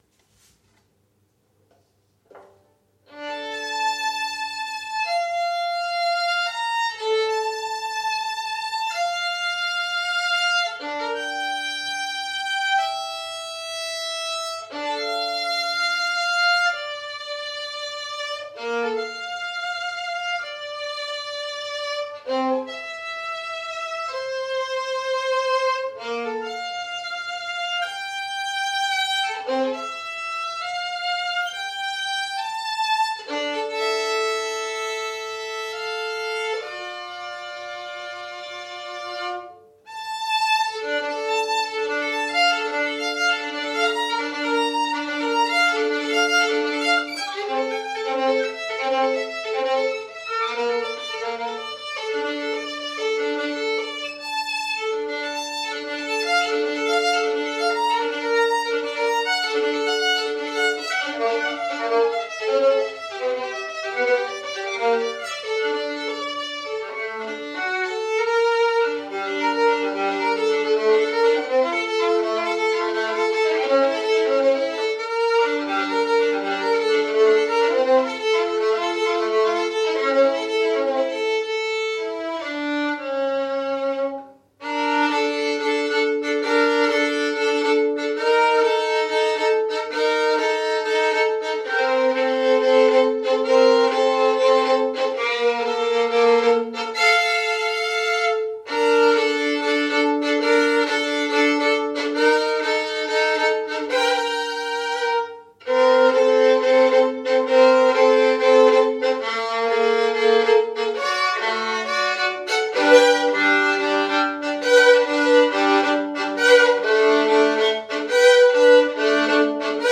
כינור סולו